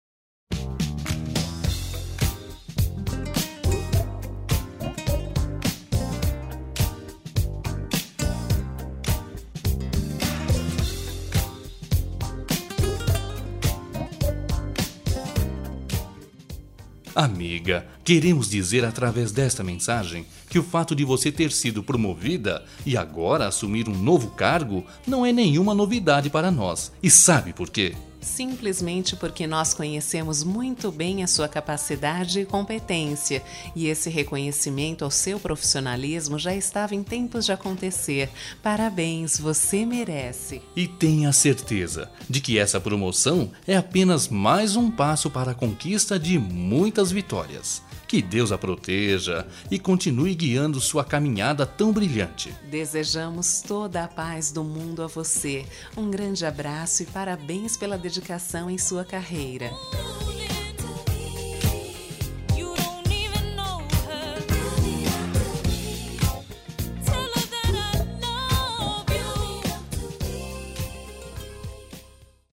Duas Vozes